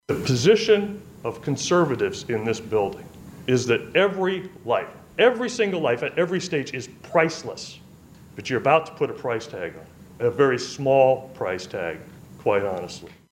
CHIP BALTIMORE, A FORMER REPUBLICAN LEGISLATOR, SAYS 97 MILLION DOLLARS ISN’T EGREGIOUS FOR THE FAMILY OF THE BABY BOY WHO WILL REQUIRE 24/7 MEDICAL CARE HIS ENTIRE LIFE AFTER HIS SKULL WAS CRUSHED.
BALTIMORE IS WORKING AS A LOBBYIST FOR TRIAL LAWYERS FOR JUSTICE.